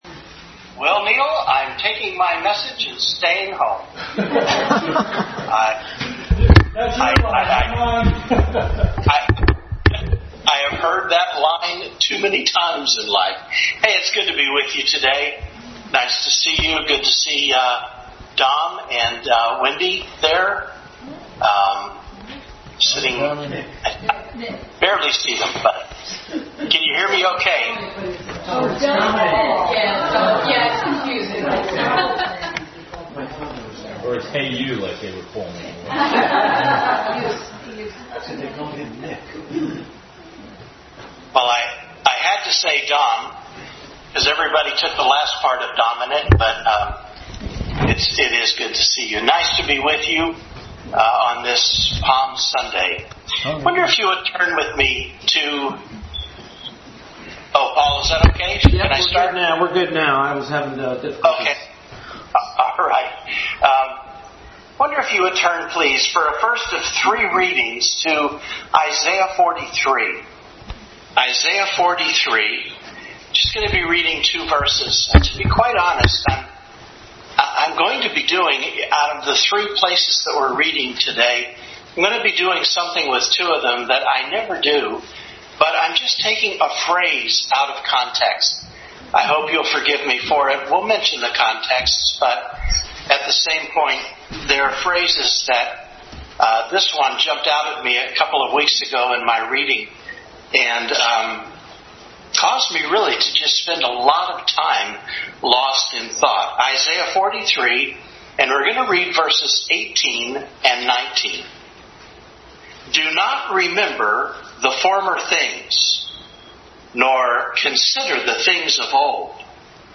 Family Bible Hour Message